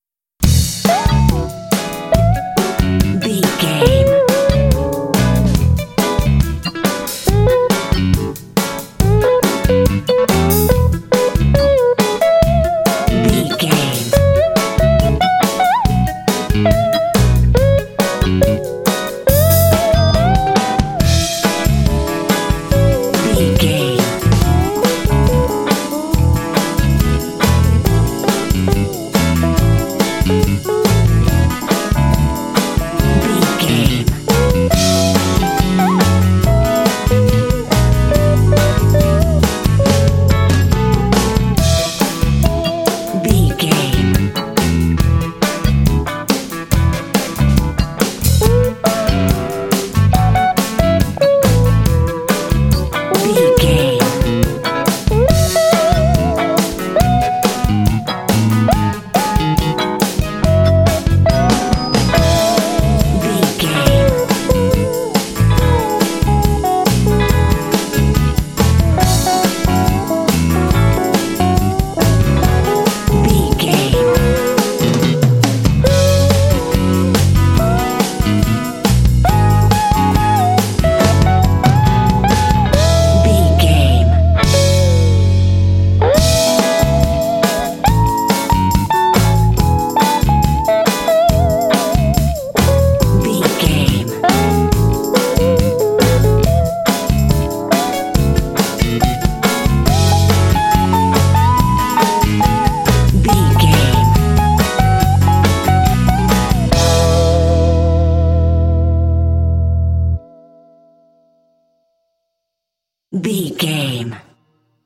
Uplifting
Ionian/Major
funky
electric guitar
bass guitar
drums
electric organ
electric piano
Funk